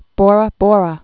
(bôrə bôrə)